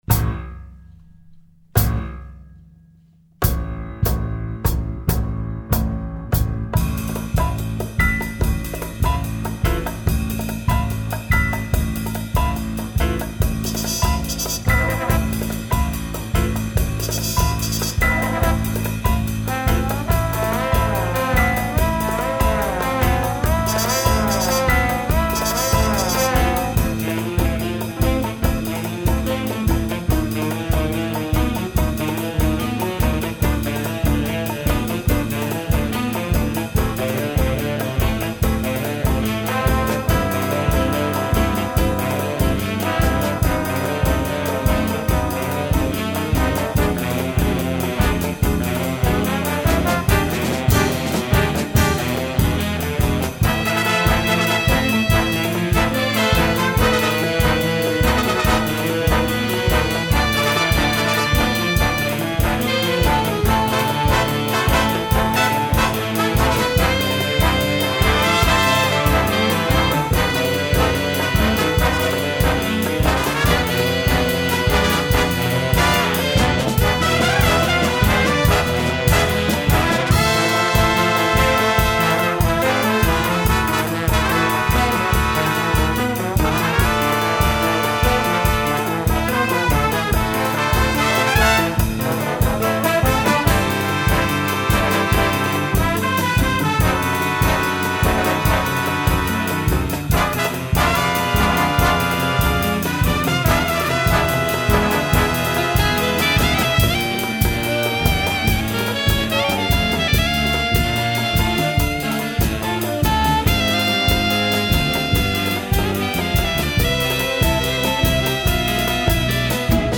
Recueil pour Harmonie/fanfare - Big Band